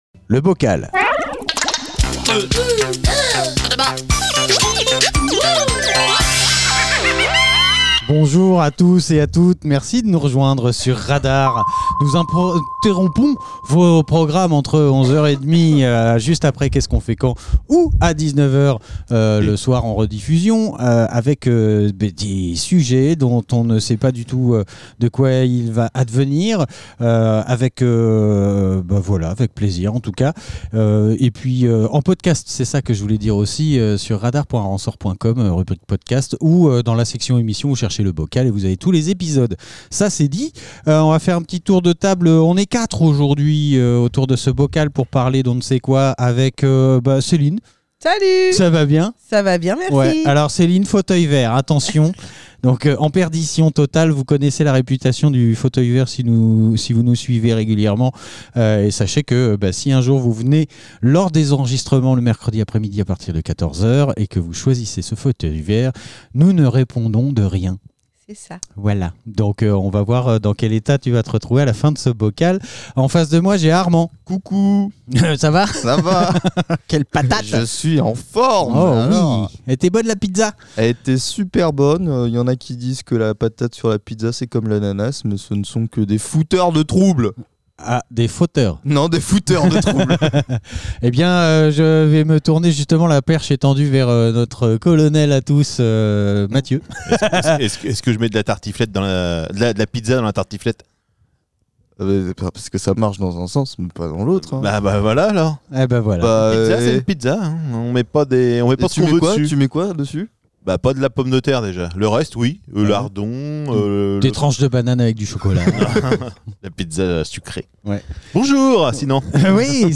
Une poignée d'invités propose des sujets de débats à bulletin secret. Ces petits papiers sont délicatement mélangés pour n'en tirer qu'un seul au sort. Le sujet, une fois dévoilé, donne lieu à des conversations parfois profondes, parfois légères, toujours dans la bonne humeur !